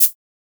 Closed Hats
edm-hihat-01.wav